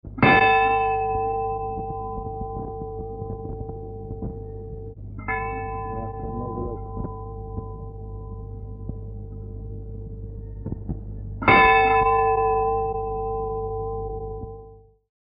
Church bells ringing